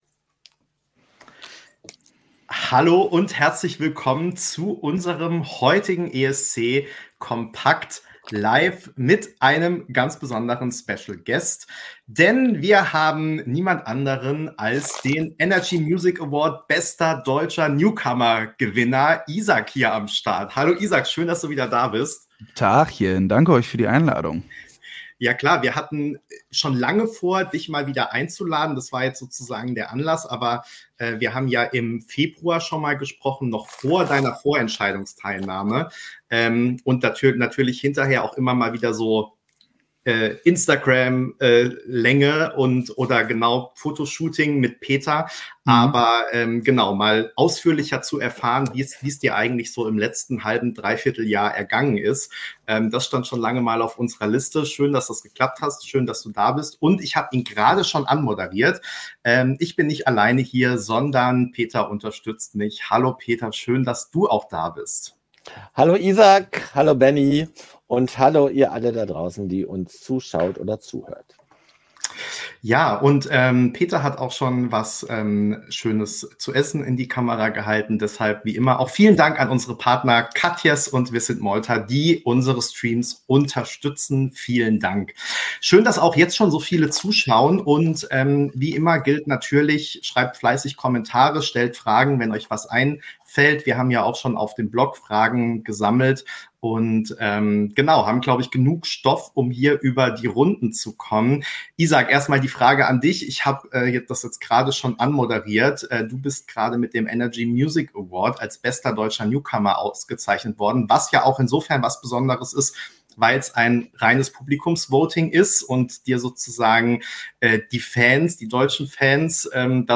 ESC kompakt LIVE